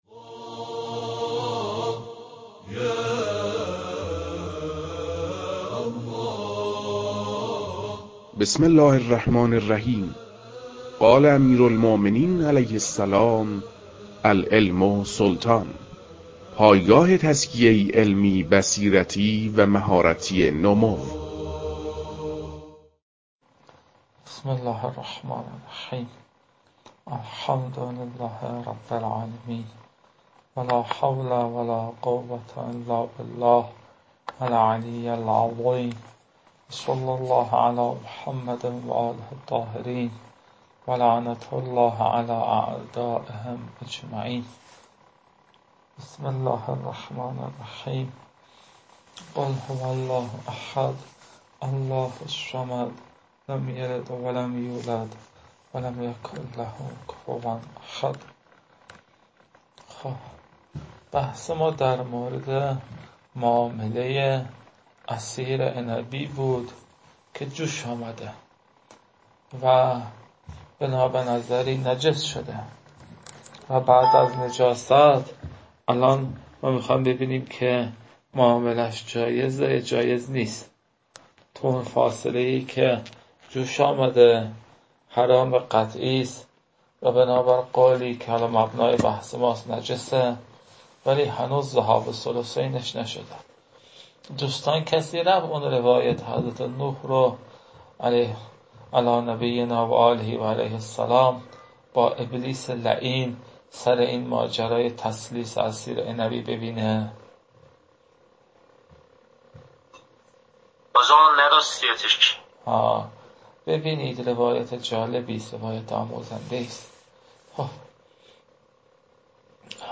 در این بخش، فایل های مربوط به تدریس بخش نخست كتاب المكاسب